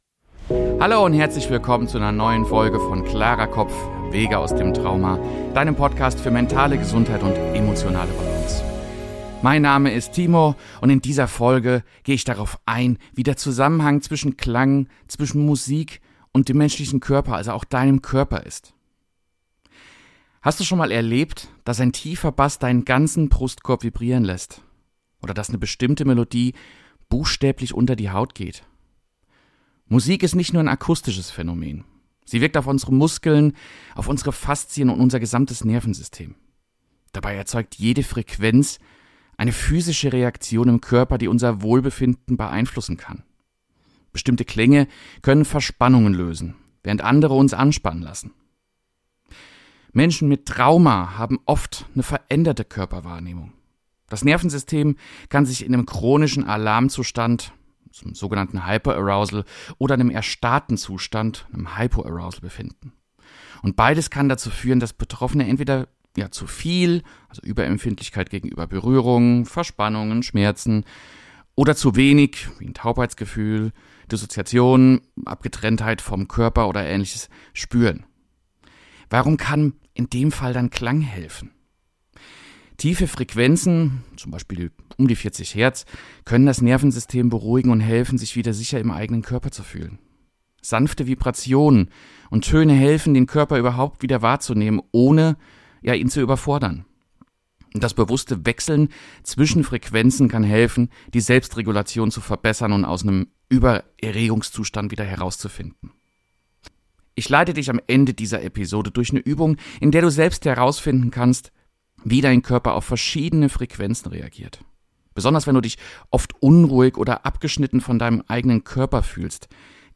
🎧 Diese Episode ist interaktiv – hör dir Klangbeispiele an & teste selbst, wie dein Körper darauf reagiert!